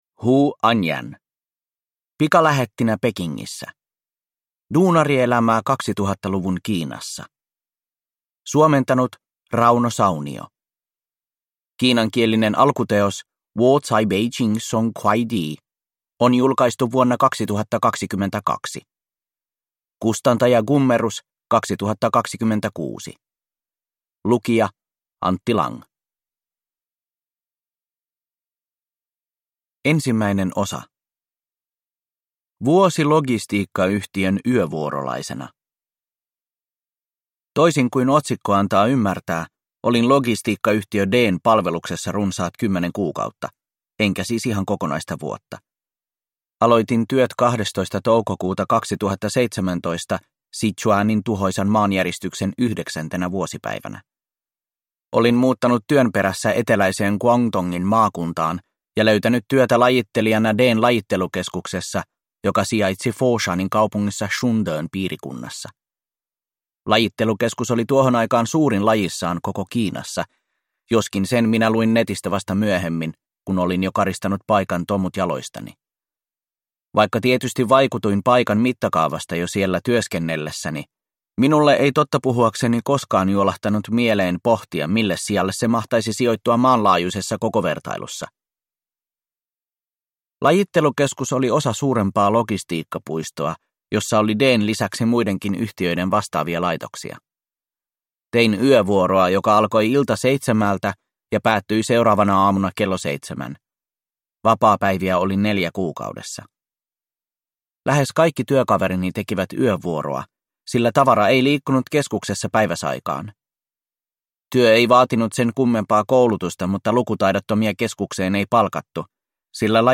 Pikalähettinä Pekingissä – Ljudbok